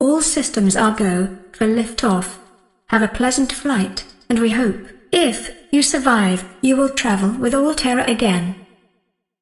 Rocket_Liftoff.ogg